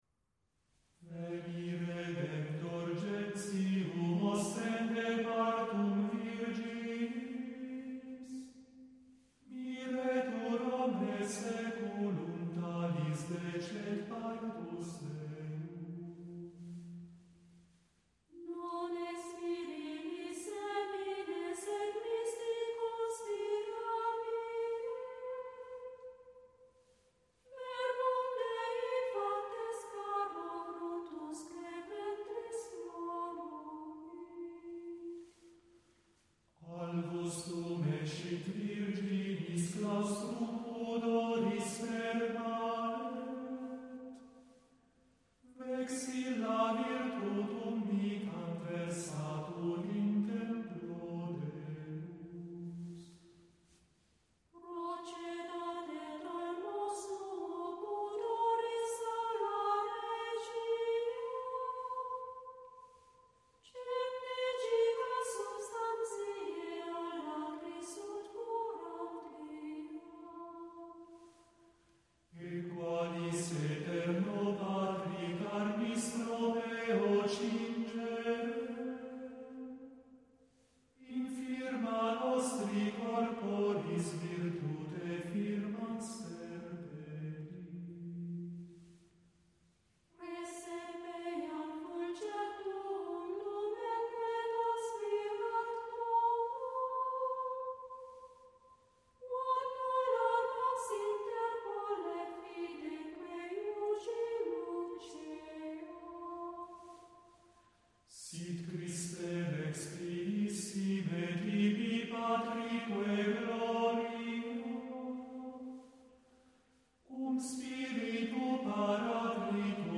Se i salmi sono i canti di tradizione ebraica – in prosa e tradizionalmente cantillati – gli inni sono i canti nuovi cristiani, in versi, intonati su una melodia semplice e sillabica.